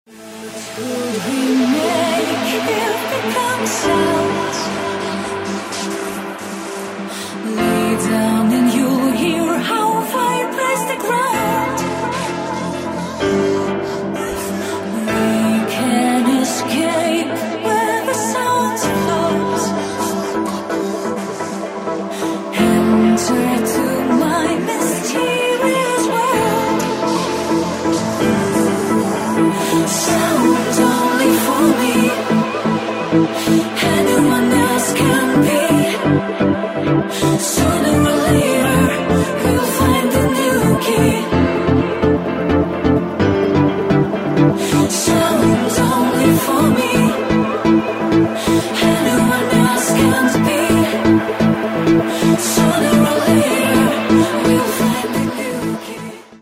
• Качество: 128, Stereo
женский вокал
dance
Electronic
house
vocal trance